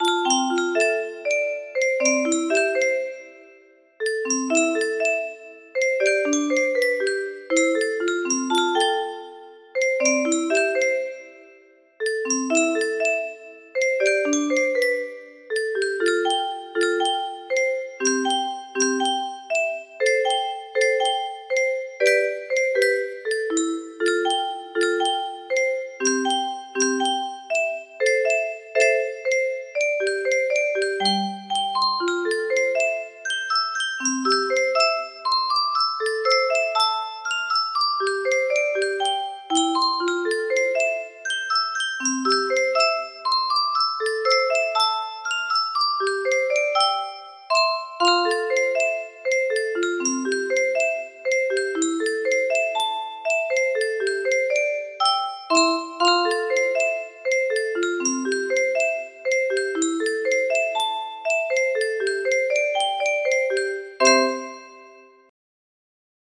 Celestial music box melody
Grand Illusions 30 (F scale)